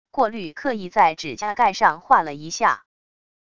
过滤刻意在指甲盖上划了一下wav音频